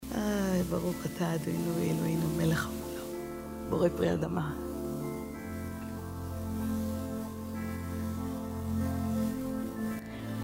רעשי רקע בפרימייר
אולי יש פה מישהו שמתמצא, הסרטתי הופעה והיה אייס בהקלטה אני חייבת להוריד אותה ניסיתH כבר להשתמש בDENOISE וזה לא עזר יש למישהו רעיון אחר שממש יציל אותי????